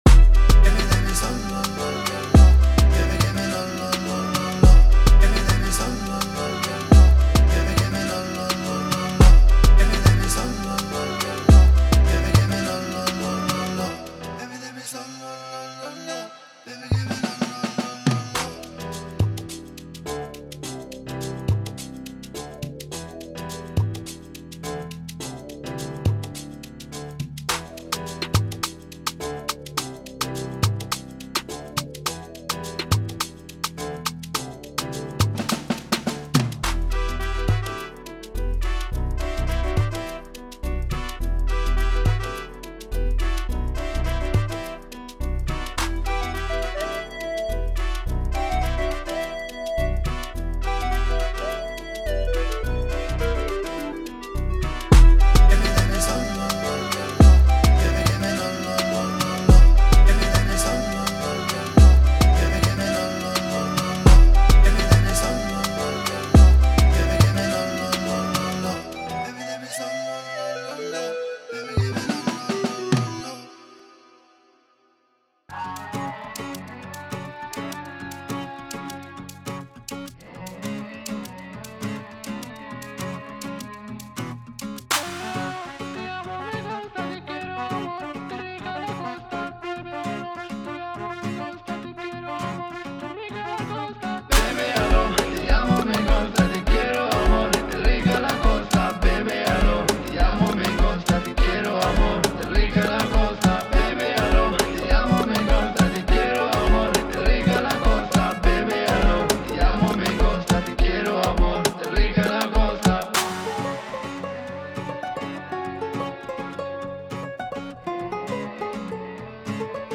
• 50 Vocals & Harmonies
• 50+ Drums & Percussions
• 30+ Chords & Melodies
Demo